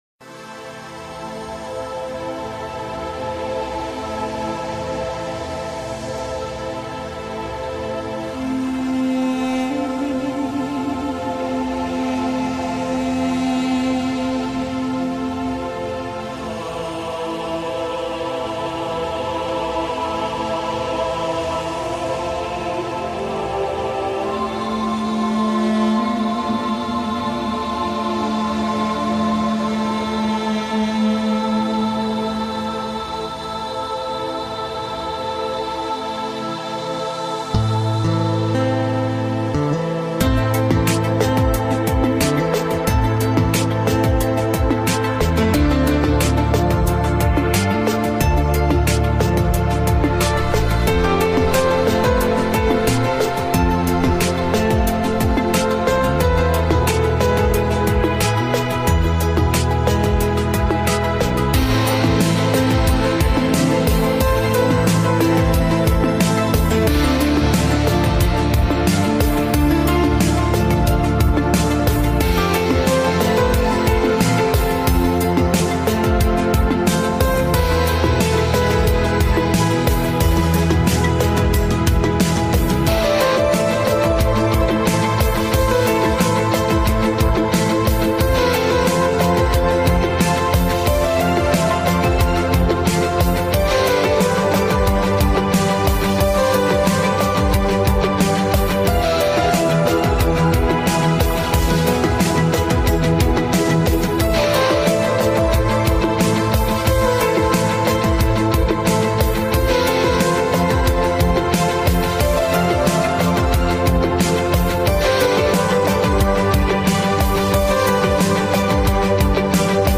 По-моему, это стиль нью-эйдж.